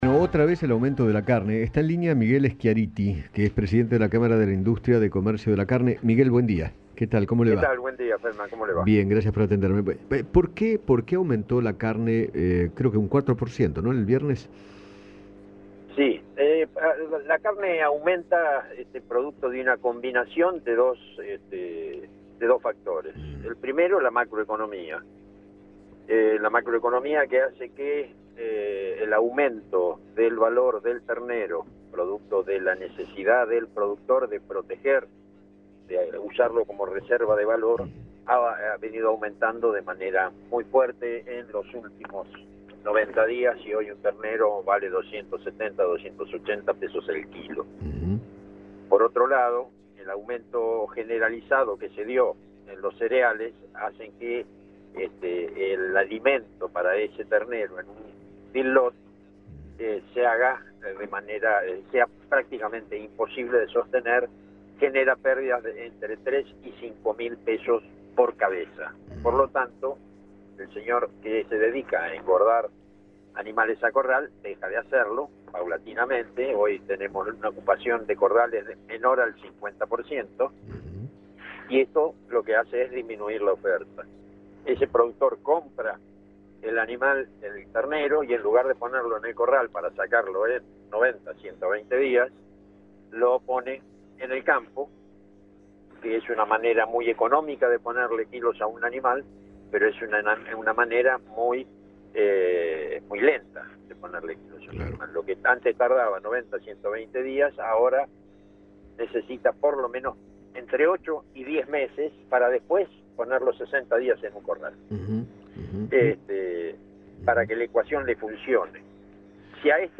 dialogó con Eduardo Feinmann sobre el incremento del precio de la carne y explicó por qué se generó.